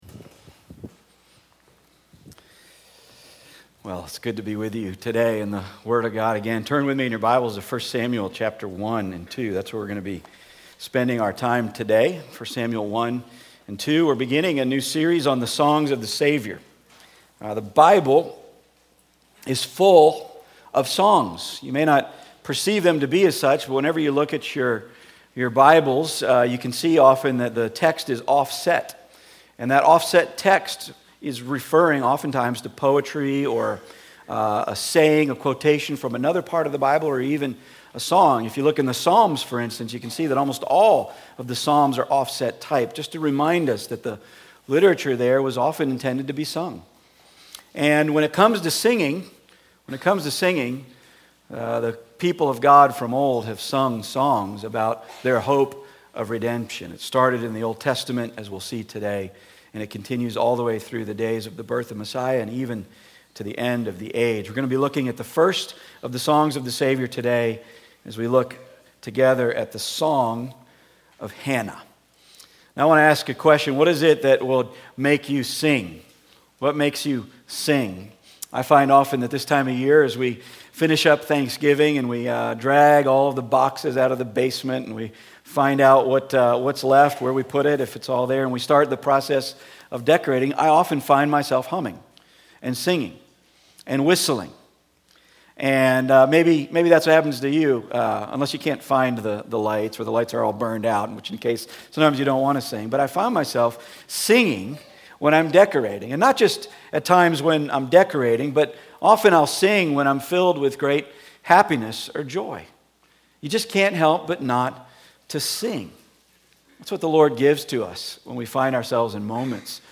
Passage: I Samuel 1-2 Service Type: Weekly Sunday